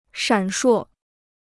闪烁 (shǎn shuò): flickering; twinkling.